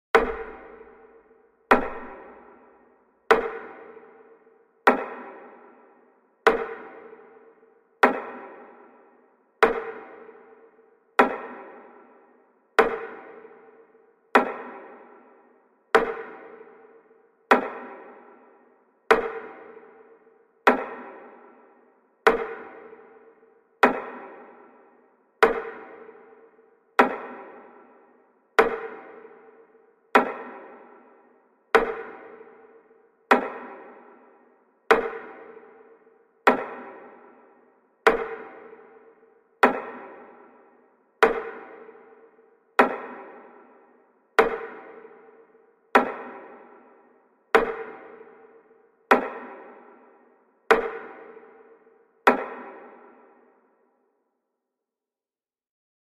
Тихий звук молчания